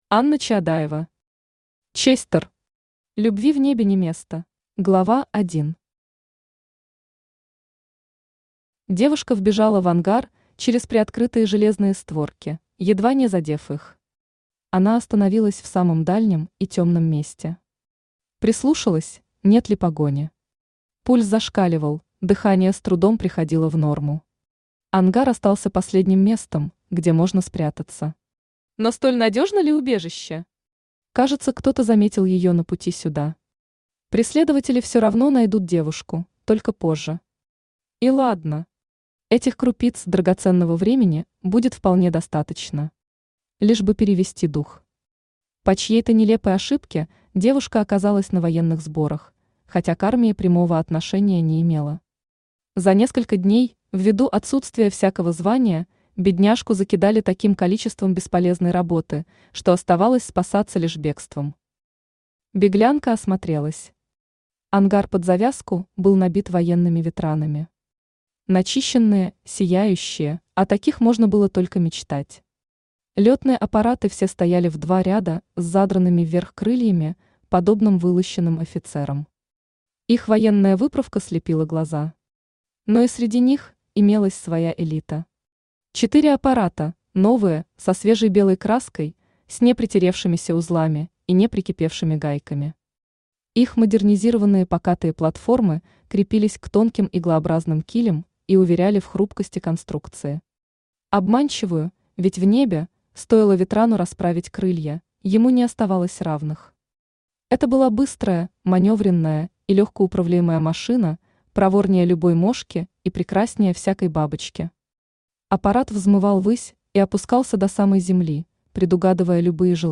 Читает: Авточтец ЛитРес
Аудиокнига «Честер. Любви в небе не место».